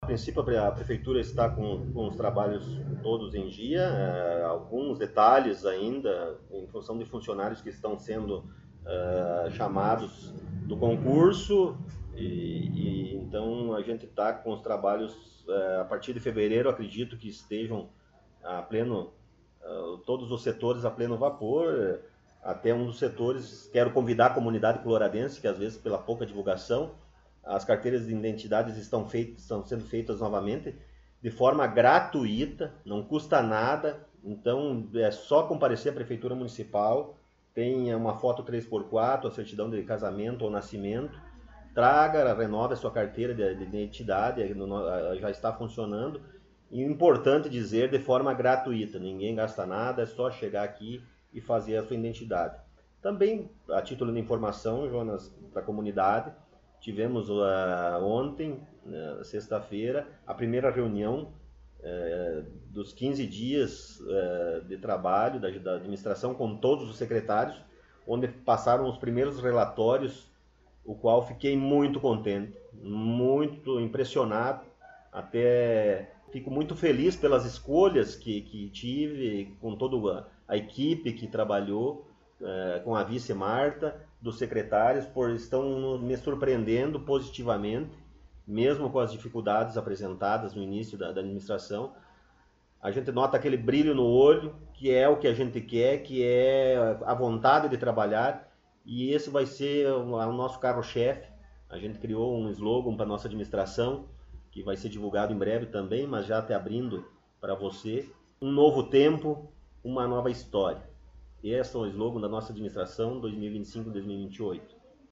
Prefeito Municipal Rodrigo Sartori concedeu entrevista